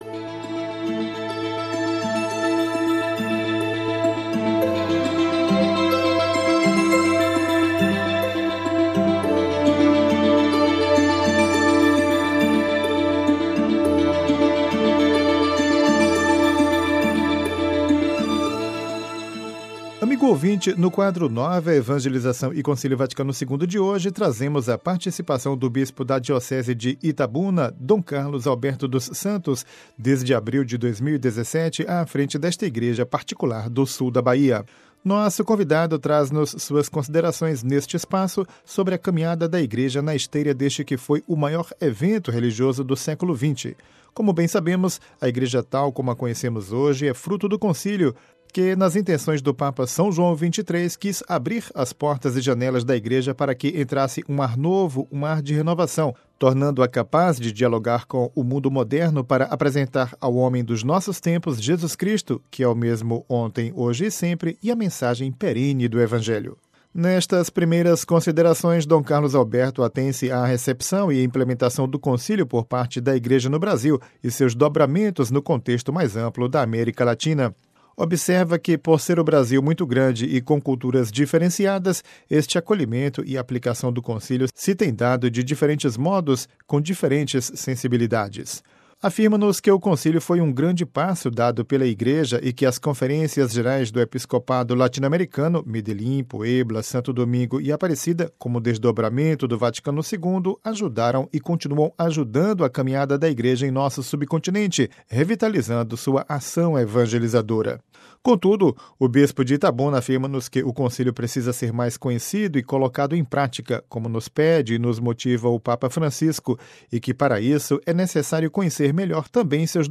Amigo ouvinte, no quadro “Nova Evangelização e Concílio Vaticano II” de hoje trazemos a participação do bispo da Diocese de Itabuna, Dom Carlos Alberto dos Santos, desde abril de 2017 à frente desta Igreja particular do sul da Bahia.